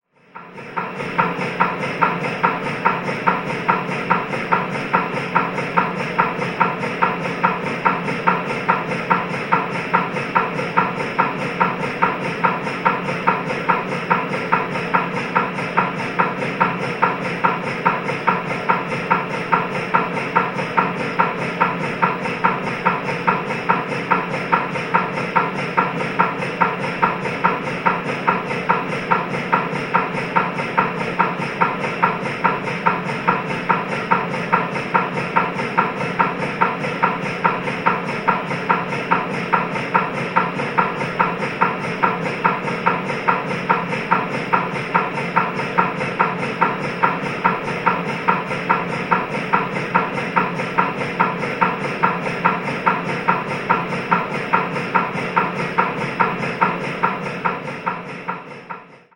На этой странице представлены звуки работы аппарата МРТ – от ритмичных постукиваний до гудения разной интенсивности.
Звуки МРТ томографии: Шум работающей машины МРТ настоящая запись из госпиталя